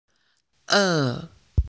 「ウ」と「ア」の中間のような濁った発音です。
「餓 è」の発音
chinese-pronunciation-e-2.mp3